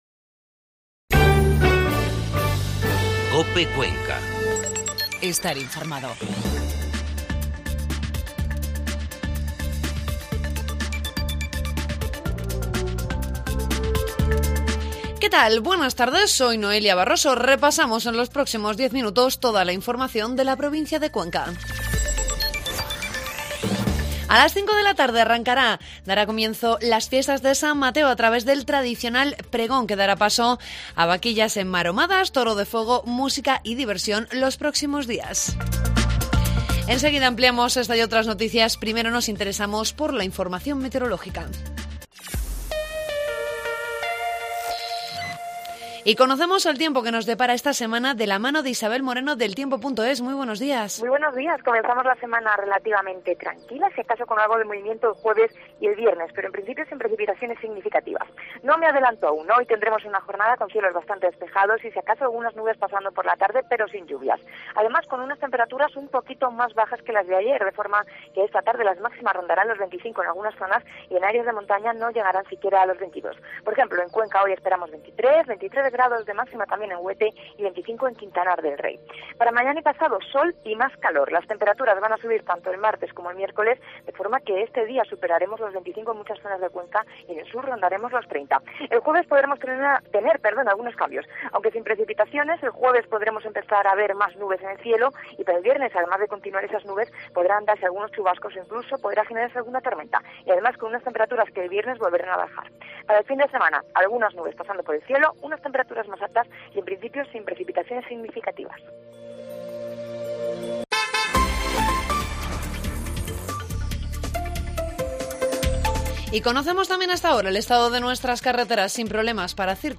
AUDIO: Informativo mediodía.